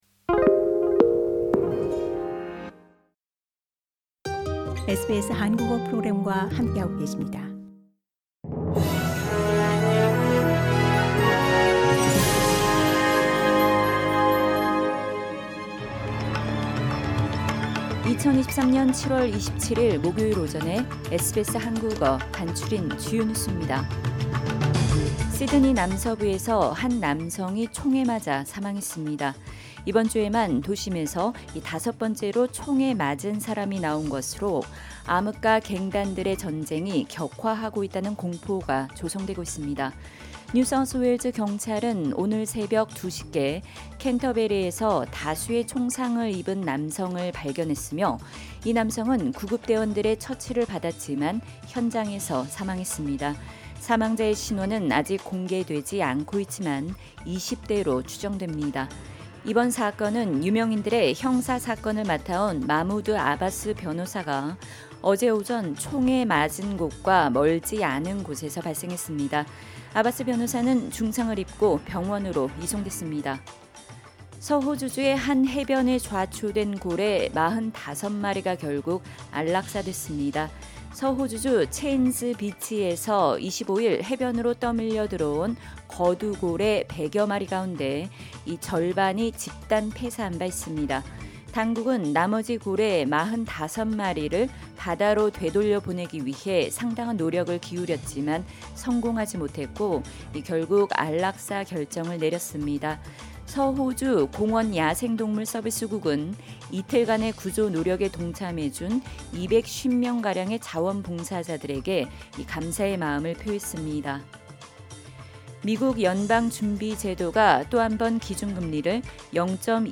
SBS 한국어 아침 뉴스: 2023년 7월27일 목요일